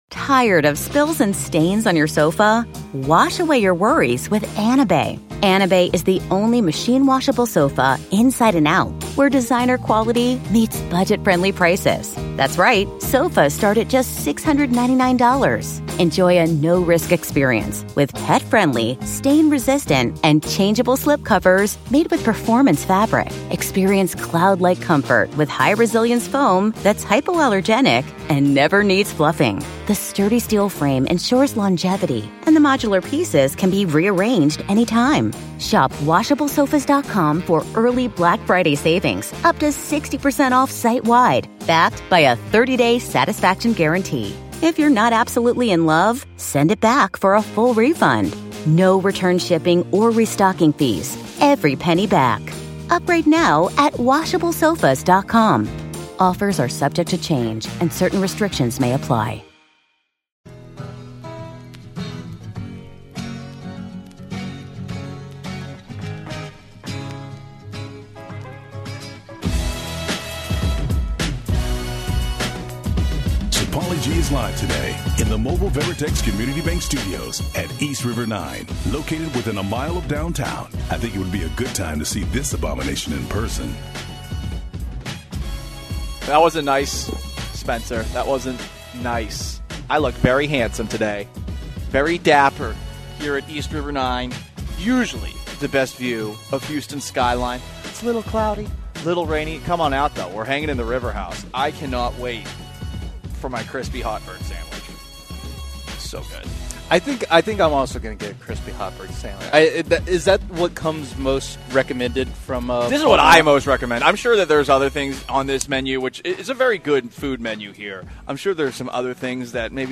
live from east river 9